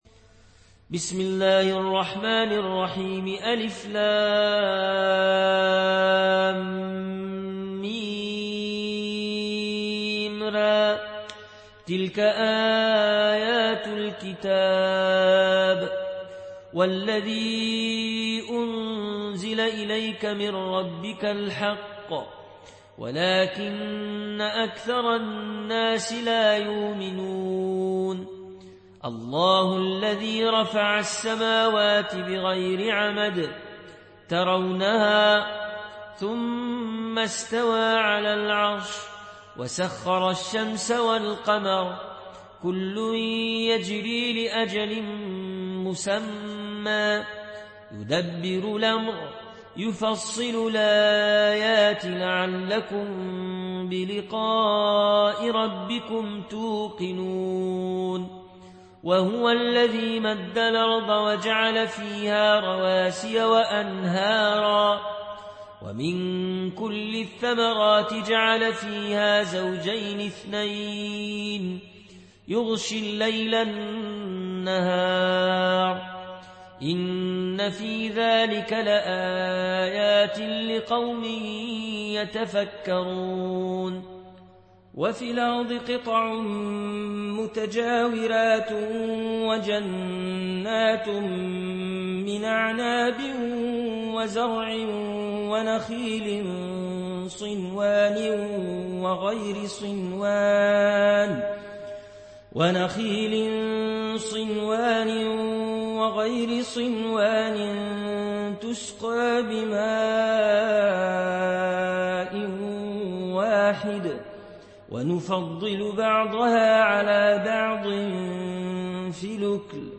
(روایت ورش)